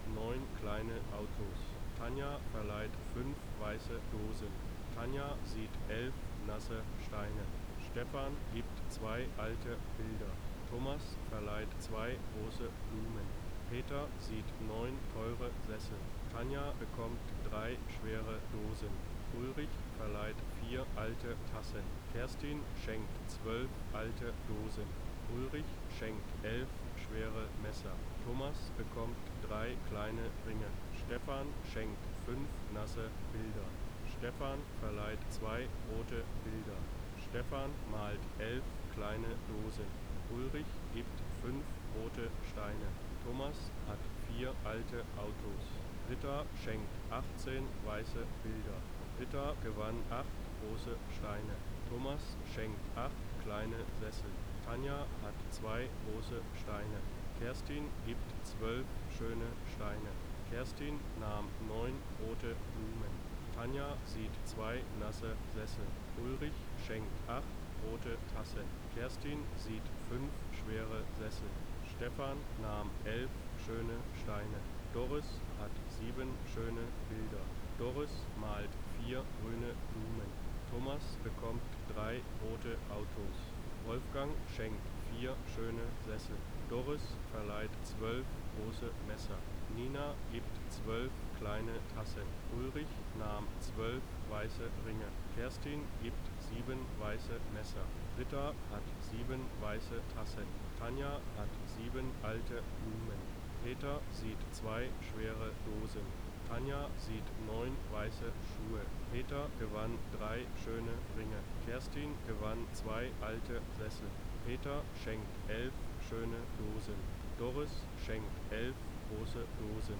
Rauschen mit Sprecher versch_SNR_R45  S48.wav